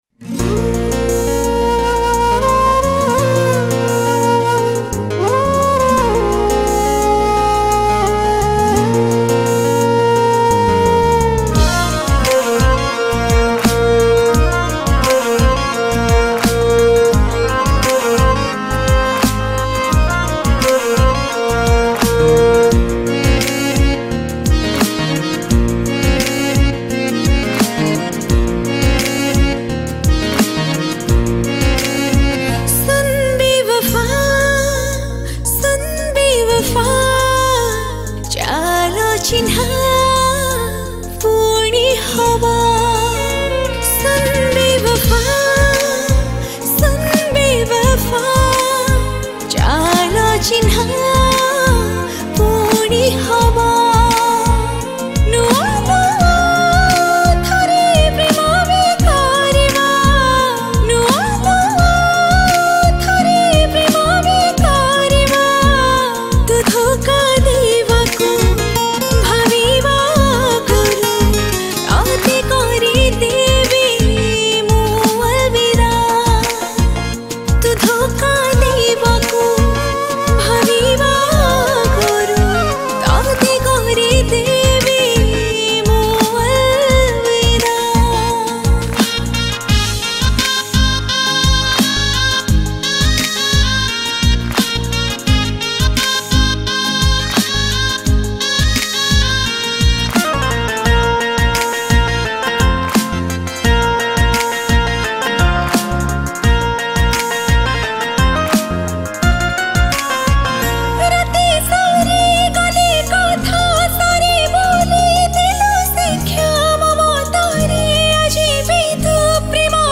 Odia Sad Romantic Song